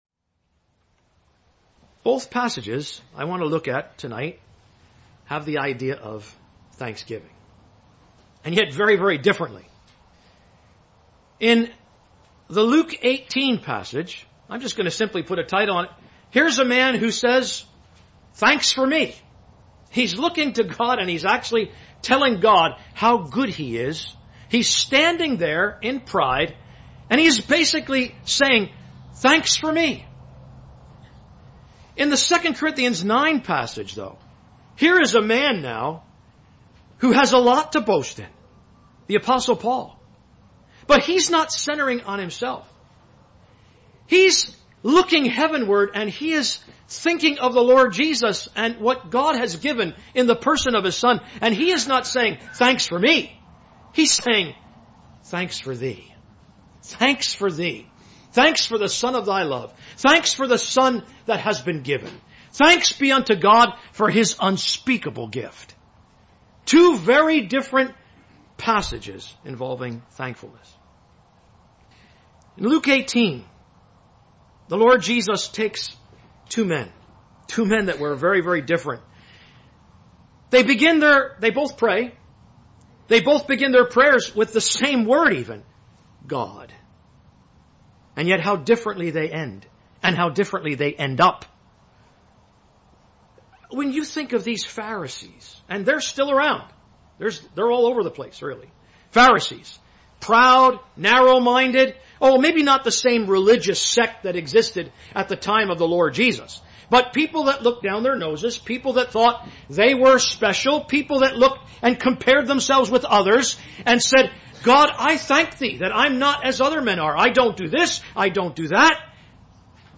You need “haste”, “humility” and, most essentially you need “Him”, the Lord Jesus. (Messages preached 5th July 2016)